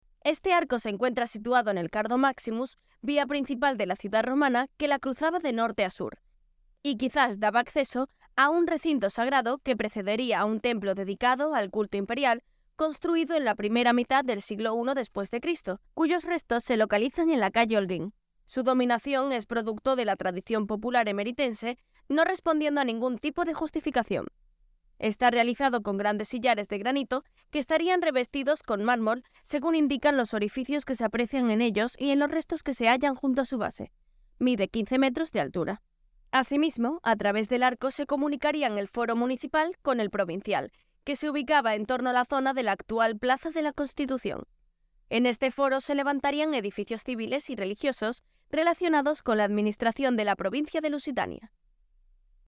Audioguía Arco de Trajano